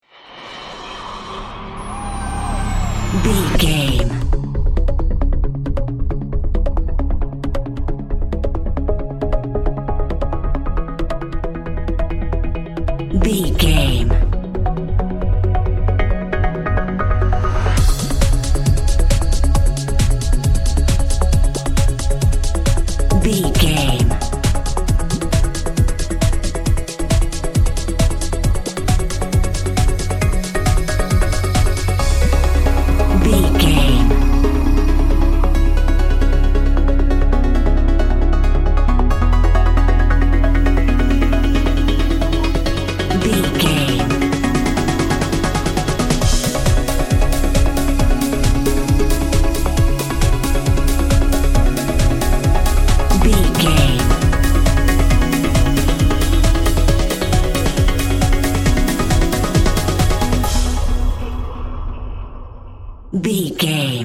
In-crescendo
Aeolian/Minor
C#
energetic
hypnotic
synthesiser
drum machine
electro house
synth lead
synth bass